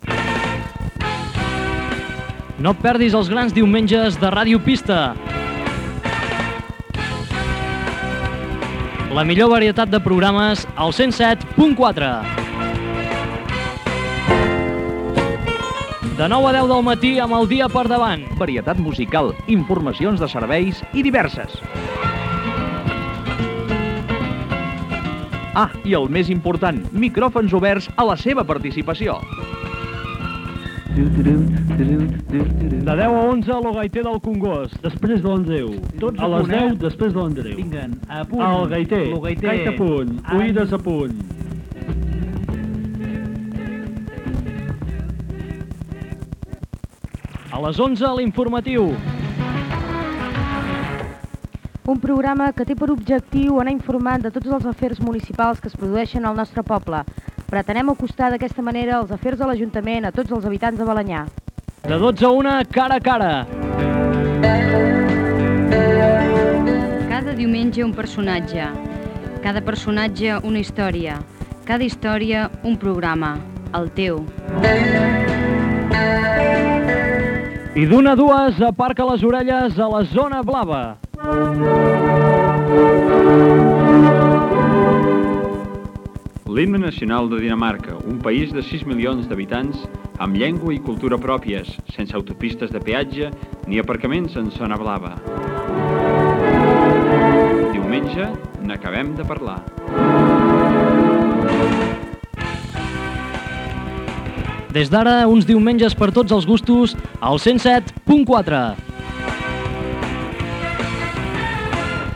Promoció de la programació dels diumenges de Ràdio Pista ("El dia per davant", "Lo gaiter del Congost", "L'informatiu", "Cara a cara", "Zona blava"
FM